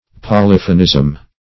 polyphonism - definition of polyphonism - synonyms, pronunciation, spelling from Free Dictionary
Polyphonism \Po*lyph"o*nism\, n.